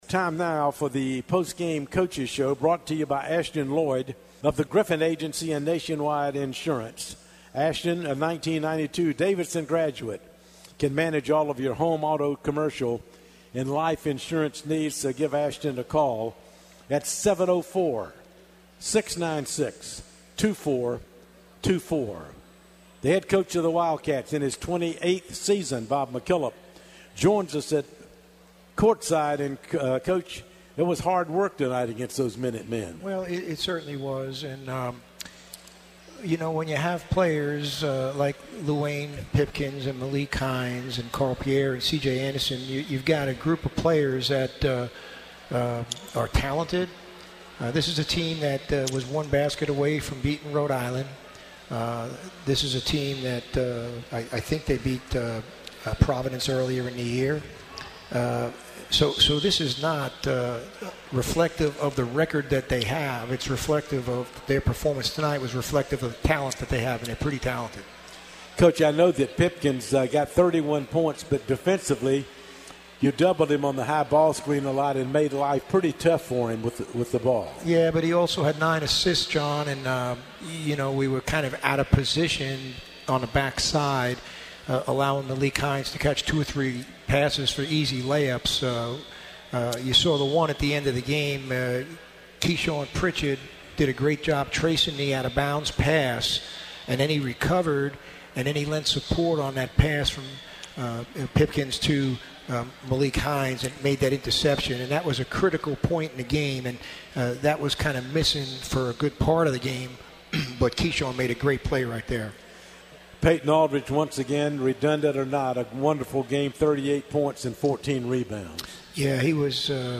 Postgame Interview